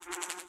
snd_beespawn.ogg